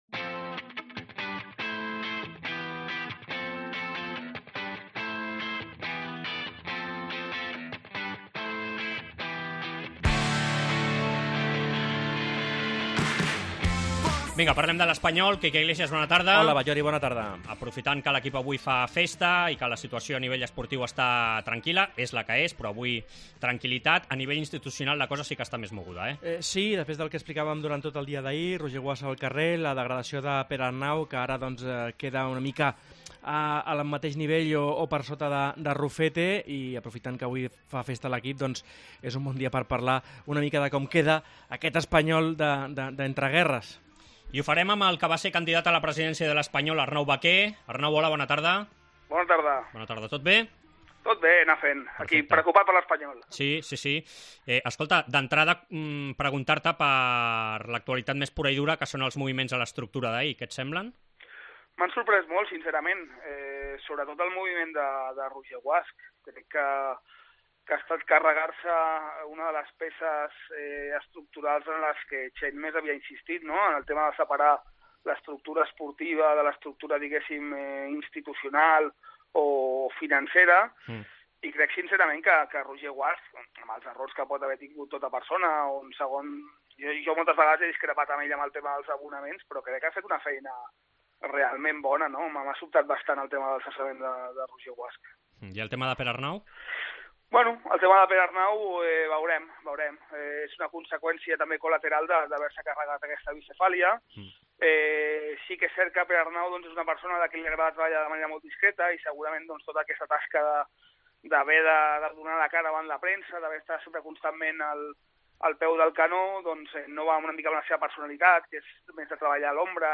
AUDIO: Entrevista con el ex candidato ael ex candidato a la presidencia del club que pide presencia de más personas españolistas en la institución